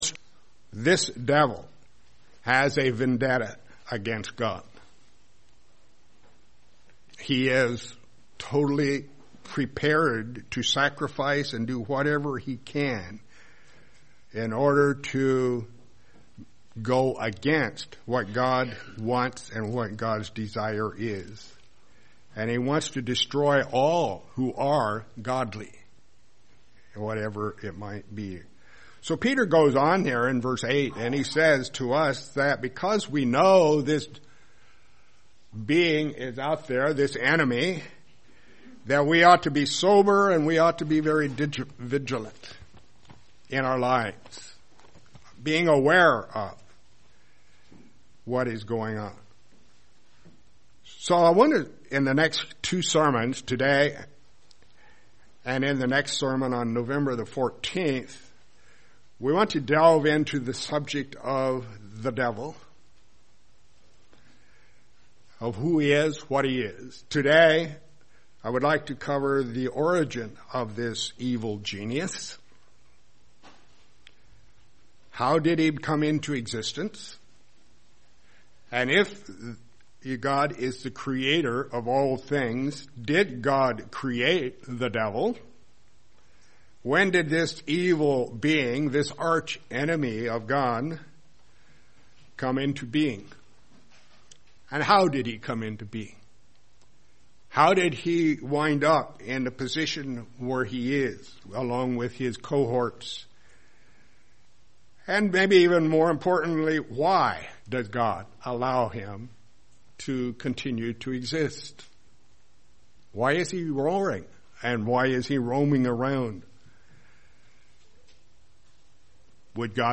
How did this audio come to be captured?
Given in Lehigh Valley, PA York, PA